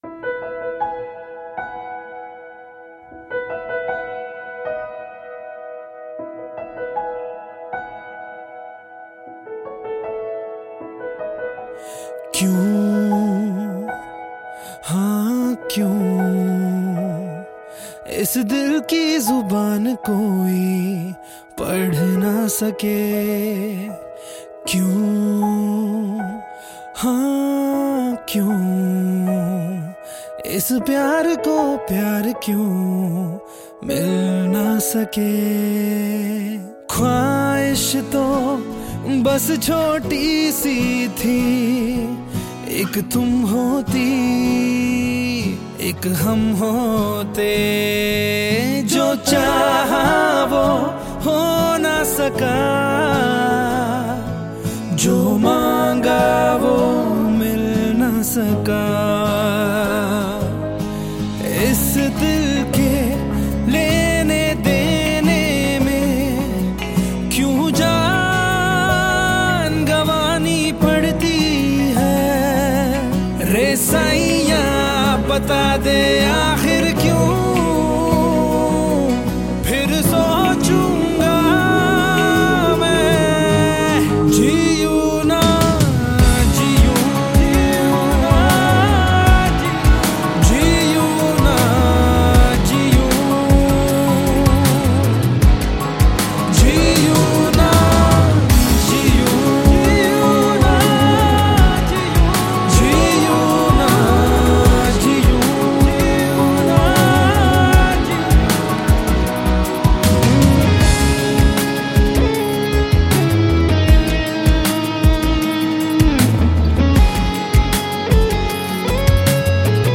Home » Bollywood Mp3 Songs » Bollywood Movies